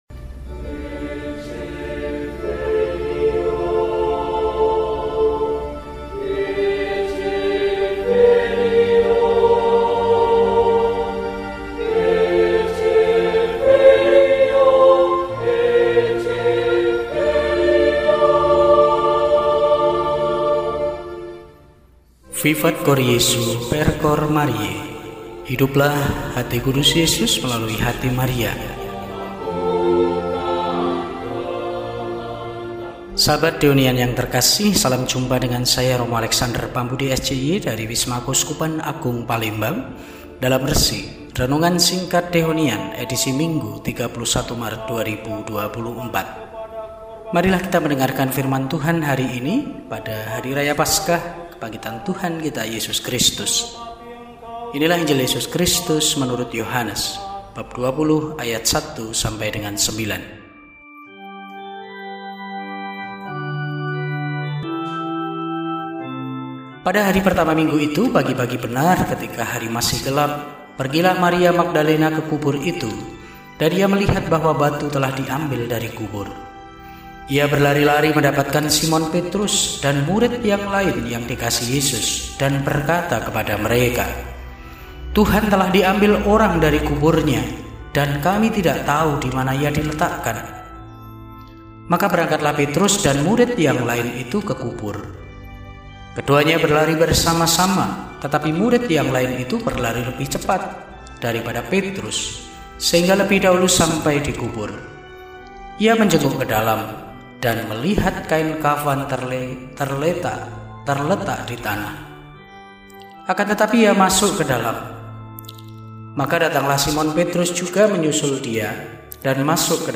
Minggu, 31 Maret 2024 – HARI RAYA PASKAH – KEBANGKITAN TUHAN (Misa Minggu Pagi) – RESI (Renungan Singkat) DEHONIAN